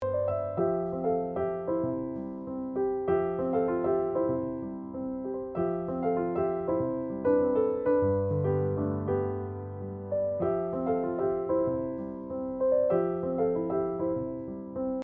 piano interpretations